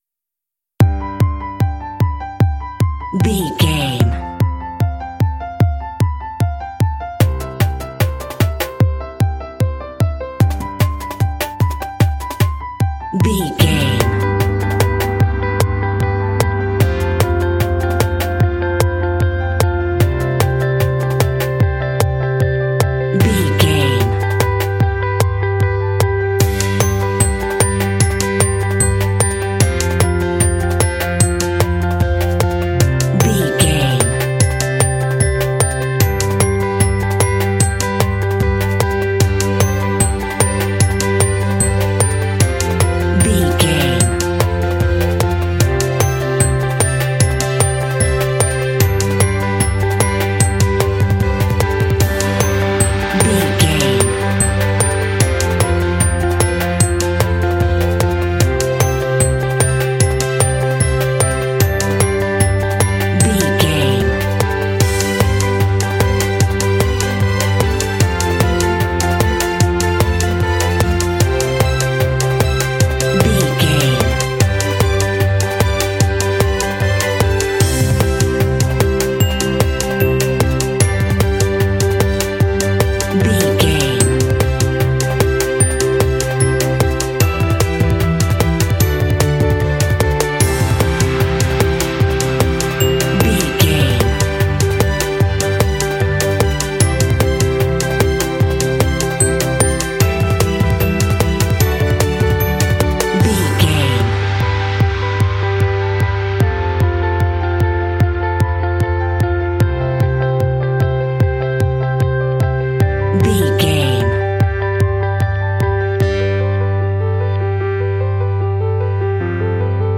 Motivational and cinematic pop track.
Uplifting
Ionian/Major
Fast
optimistic
happy
bright
piano
drums
brass
electric guitar
strings
rock
indie